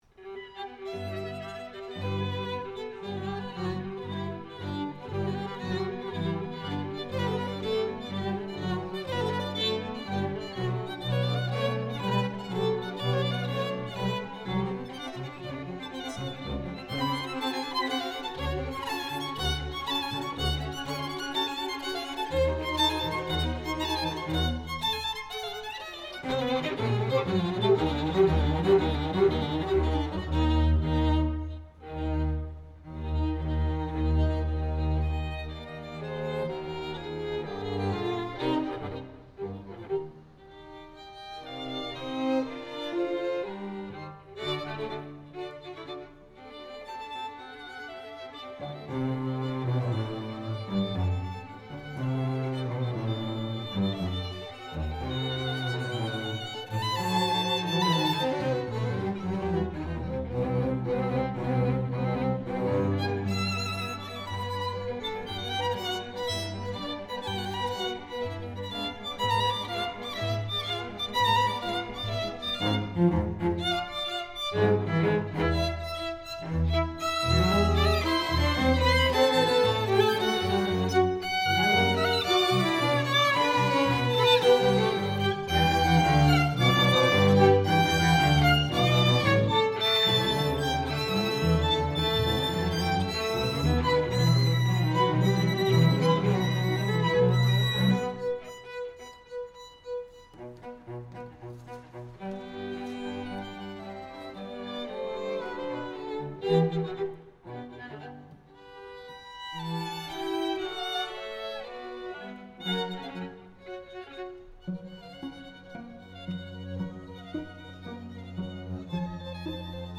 Chamber Groups
Dvorak Bass Quintet